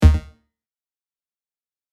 Blip 1.mp3